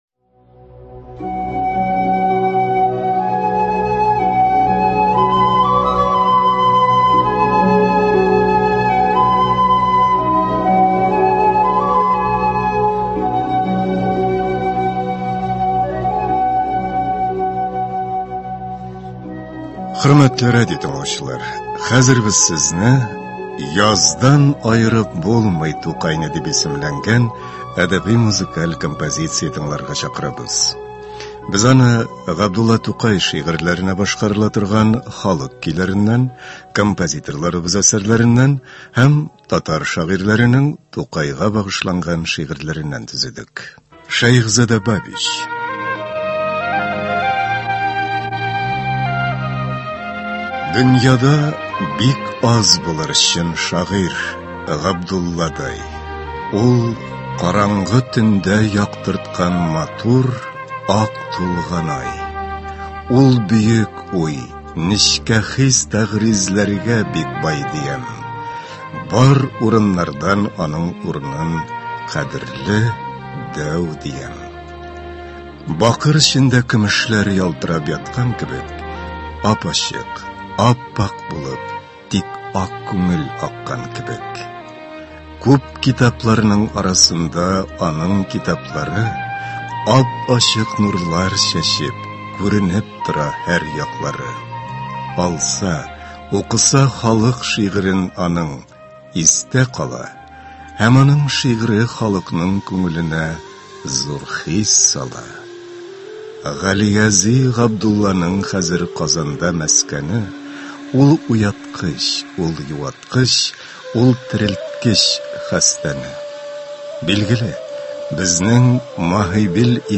Әдәби-музыкаль композиция.
Концерт (29.04.21)